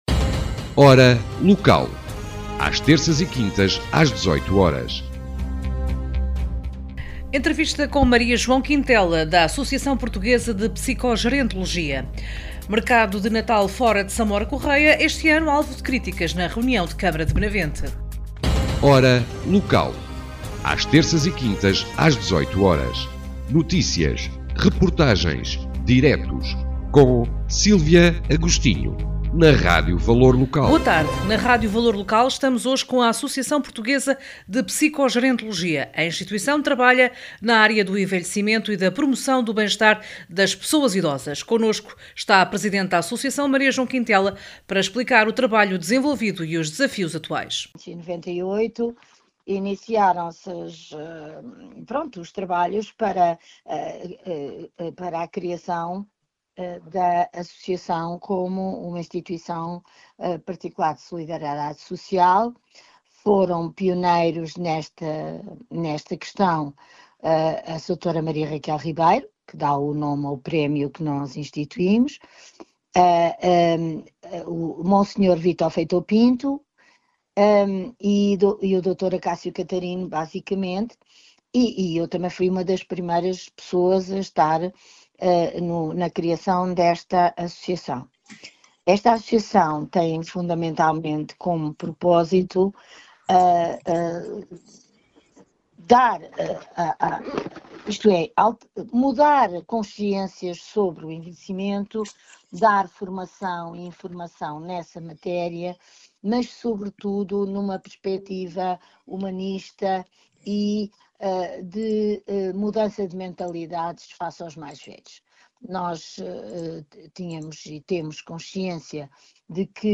Em entrevista à Rádio Valor Local